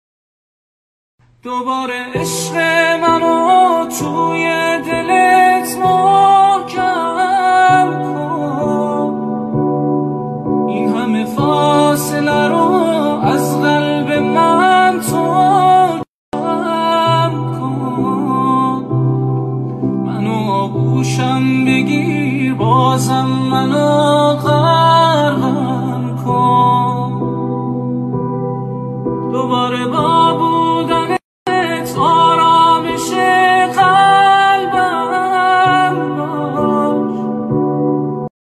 عاشقانه و غمگین
دمو منتشر شد .